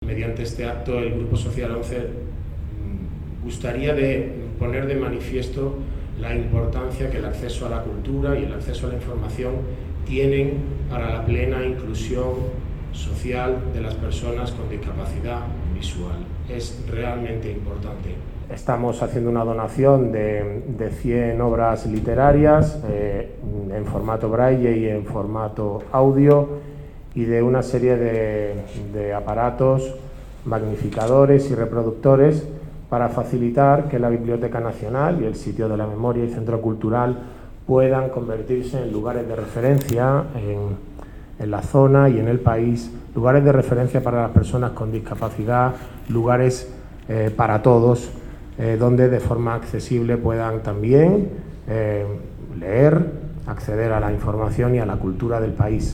En un acto celebrado en el Sitio de la Memoria y Centro Cultural de Asunción, el Grupo Social ONCE presentó la iniciativa ‘Bibliotecas para todos’ cuyo objetivo es dotar a las bibliotecas nacionales de títulos en braille y en audio, así como de las tecnologías necesarias para el acceso a su lectura, ya sea digital, sonoro o ampliado, considerando la diversidad de formatos accesibles y los medios y modos de comunicación aumentativa y alternativa.